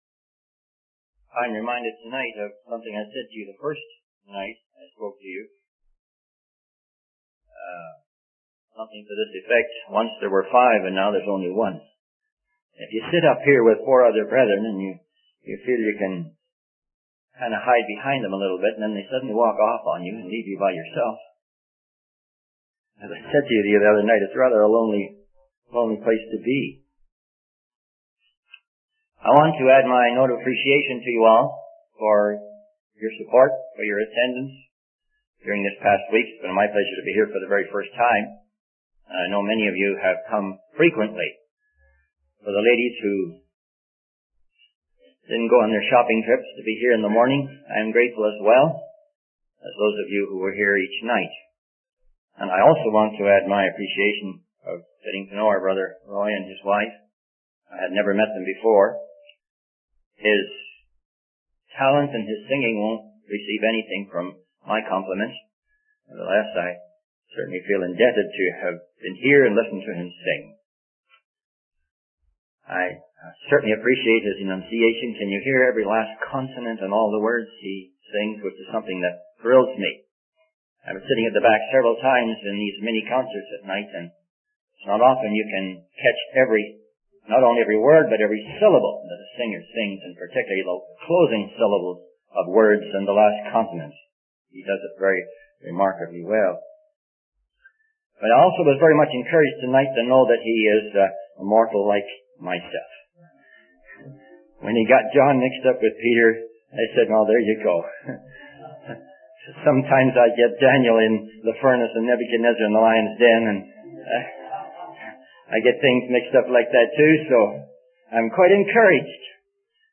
In this sermon, the preacher discusses the scene in the book of Revelation where Jesus takes the scroll from the one sitting on the throne. The preacher emphasizes the dignity and authority of Jesus as he approaches the throne.